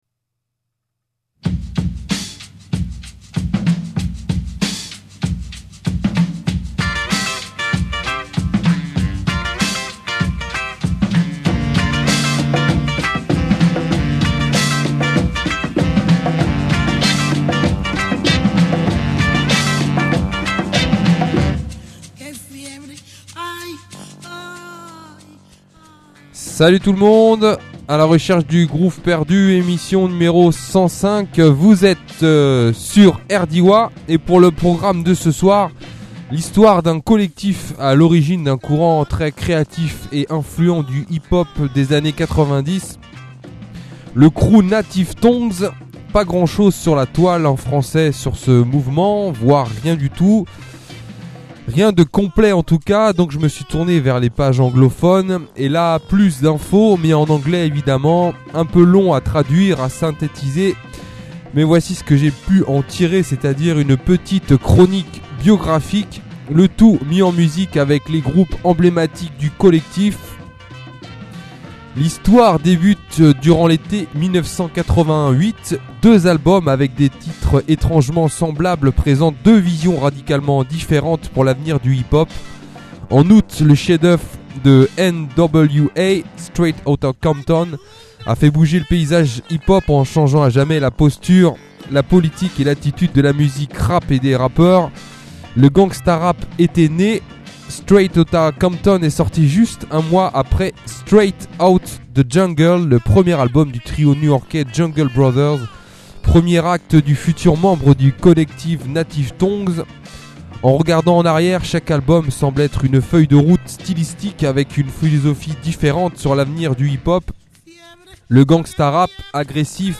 funk , hip-hop , histoire , jazz